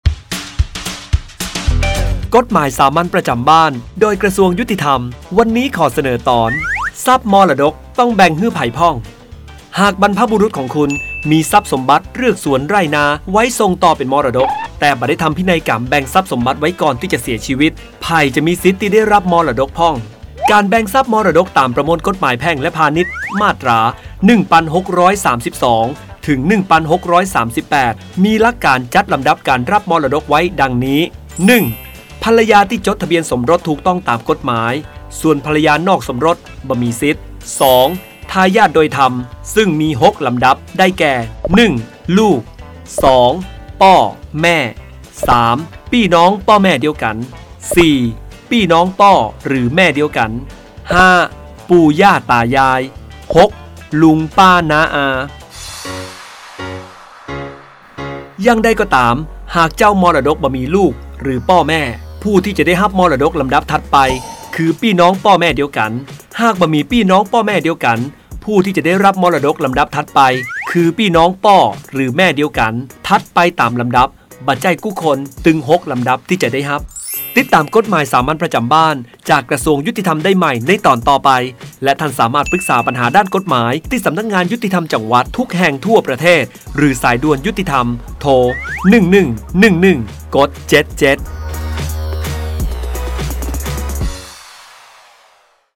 กฎหมายสามัญประจำบ้าน ฉบับภาษาท้องถิ่น ภาคเหนือ ตอนทรัพย์มรดกต้องแบ่งให้ใครบ้าง
ลักษณะของสื่อ :   คลิปเสียง, บรรยาย